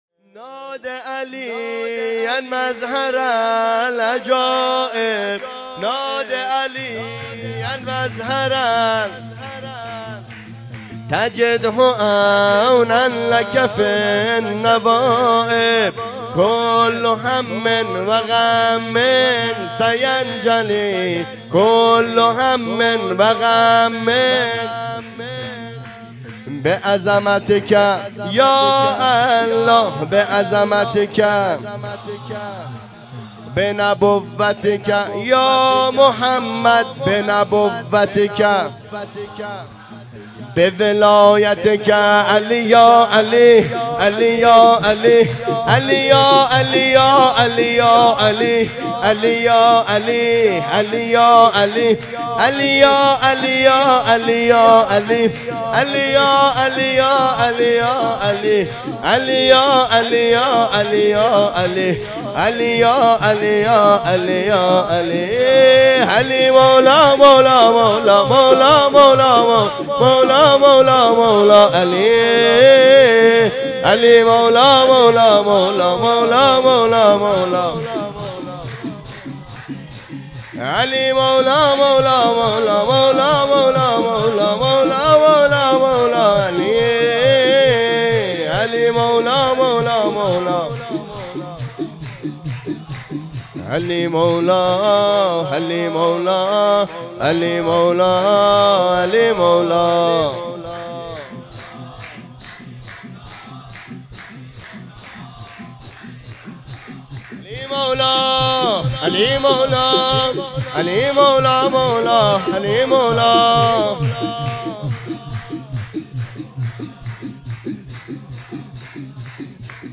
جشن بزرگ مبعث رسول مکرم اسلام1403